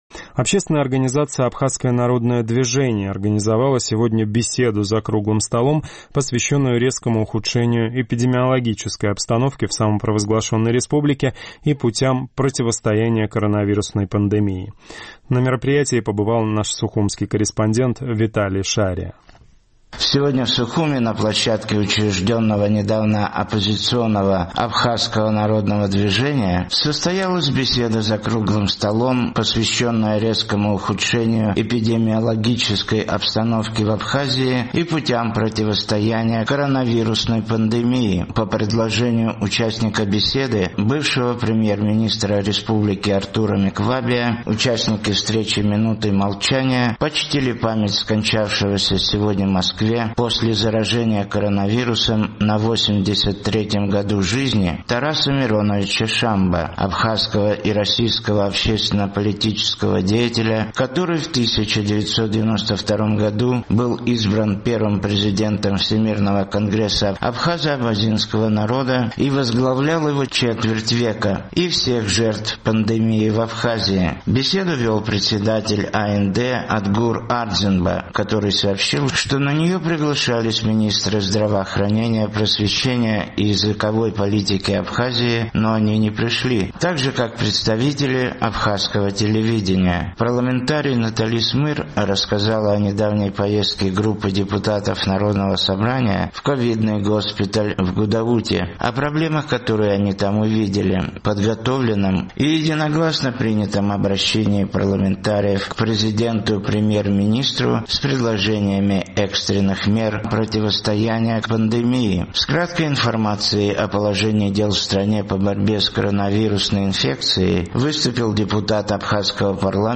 Сегодня в Сухуме, в офисном центре «Гудоу-плаза», на площадке учрежденного недавно оппозиционного «Абхазского народного движения» состоялась беседа за «круглым столом», посвященная резкому ухудшению эпидемиологической обстановки в Абхазии и путям противостояния коронавирусной пандемии.